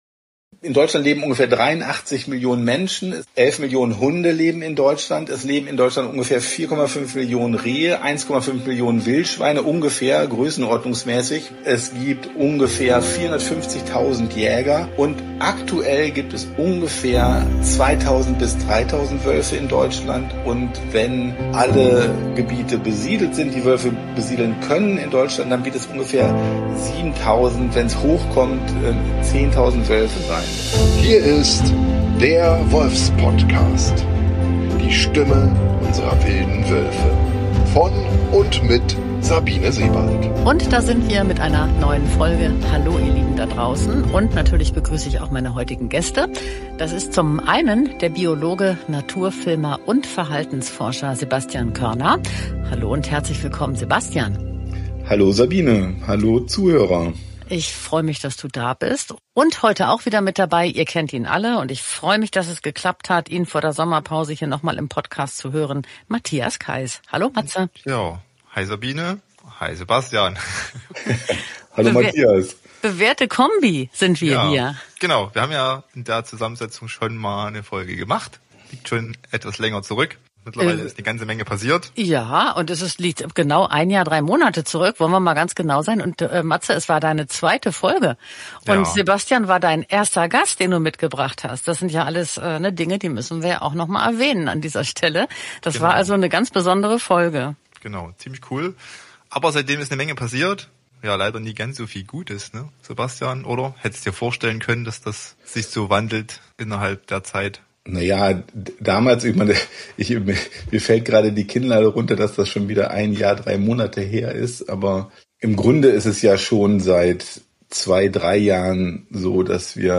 Gemeinsam erinnern sich die zwei Kollegen & Freunde an Erlebnisse und Begegnungen mit Menschen in den Wolfsgebieten. Und sie berichten über das Leben der wilden Wölfe aus ihrer ganz besonderen Sicht als Naturfilmer.